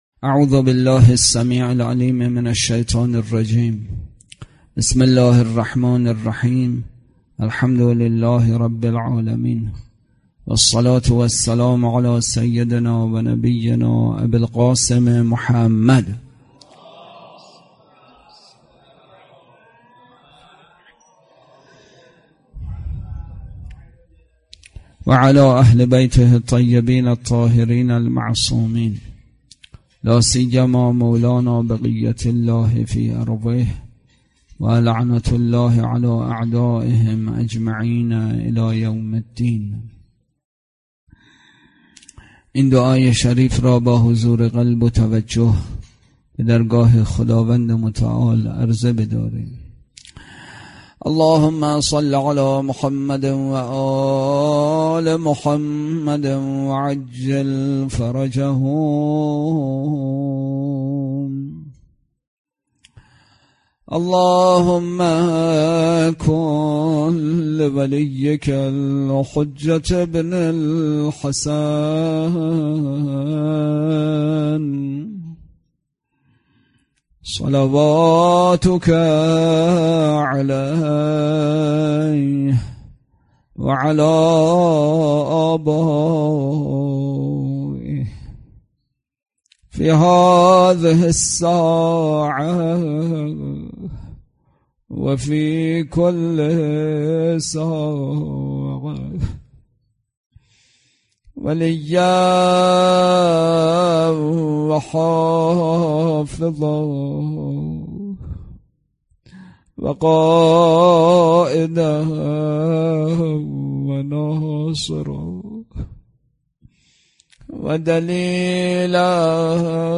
سخنرانی
برگزارکننده: مسجد اعظم قلهک